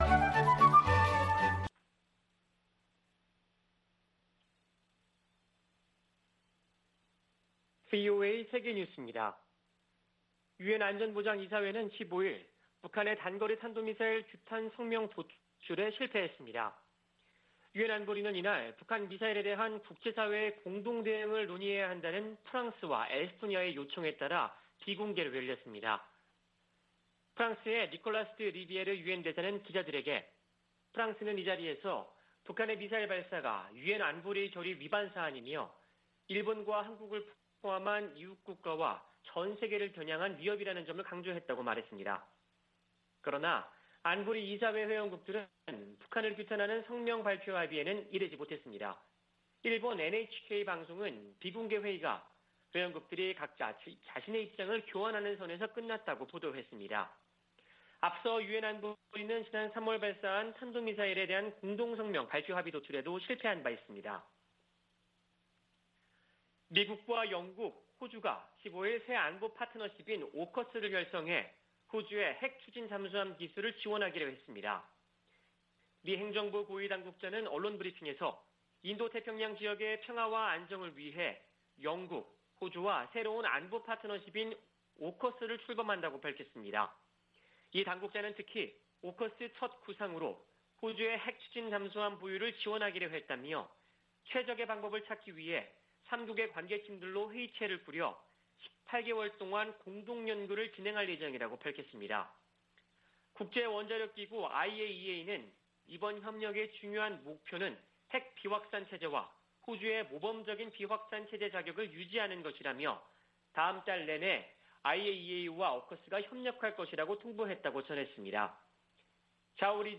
VOA 한국어 아침 뉴스 프로그램 '워싱턴 뉴스 광장' 2021년 9월 17일 방송입니다. 미국 정부가 북한의 탄도미사일 발사를 강하게 규탄했습니다. 유엔 안보리 긴급 회의에서 북한의 최근 단거리 탄도미사일 발사에 대해 논의했습니다. 미국 국무부 국제안보ㆍ비확산 담당 차관보 지명자가 인준받아 임명되면 북한 문제를 최우선 과제로 삼겠다고 밝혔습니다.